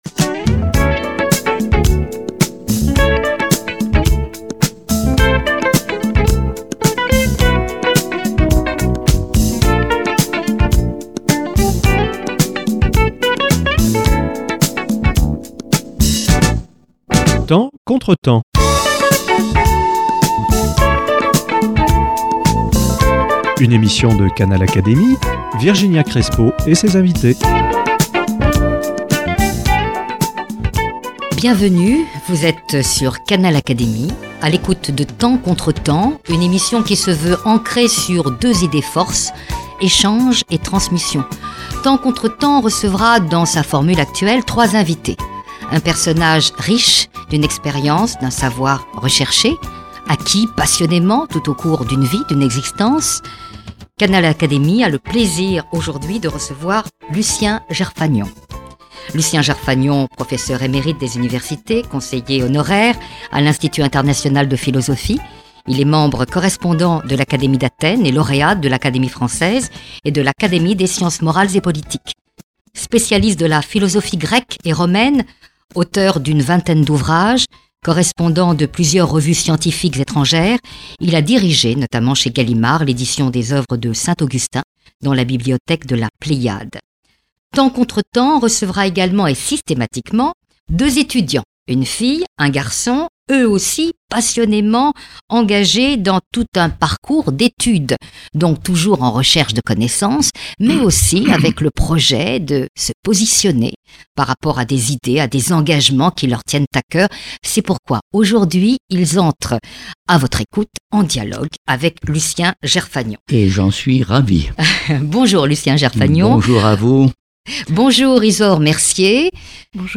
Dialogue autour de saint Augustin avec Lucien Jerphagnon
Le philosophe Lucien Jerphagnon revient, avec deux étudiants, sur ses célèbres Confessions.